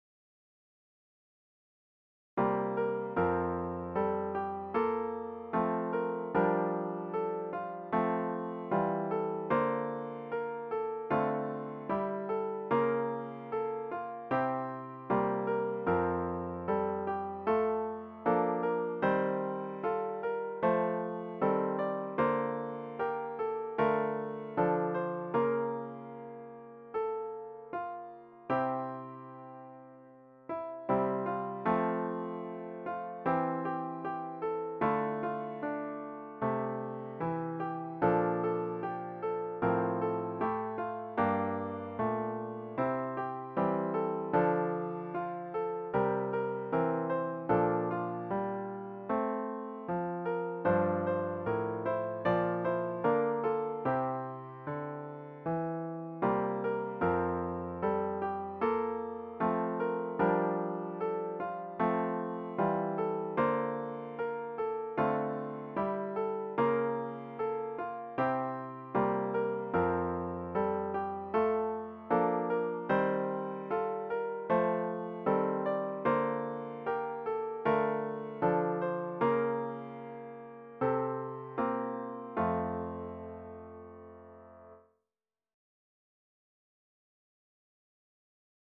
About the Hymn
The hymn should be performed at a majestic ♩= ca. 76.